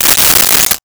Silverware Movement 04
Silverware Movement 04.wav